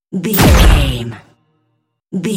Dramatic hit laser shot
Sound Effects
heavy
intense
dark
aggressive